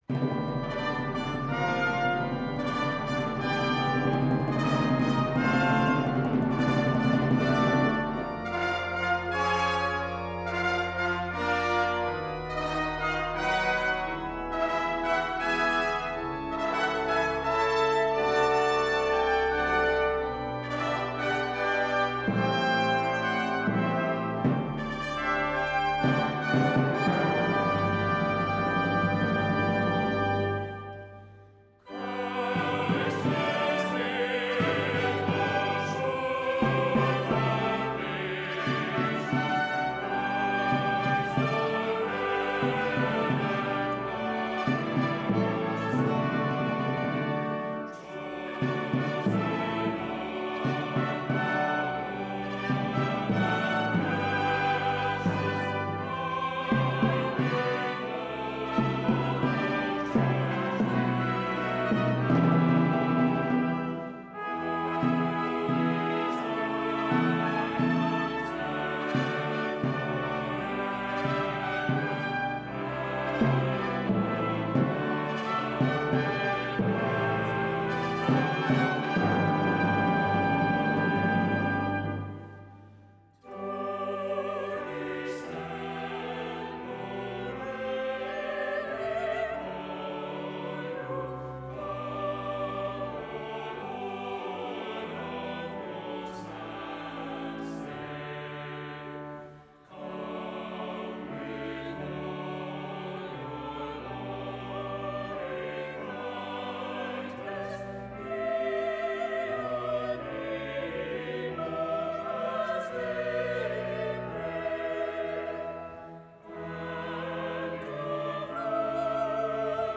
Voicing: "SATB","Assembly"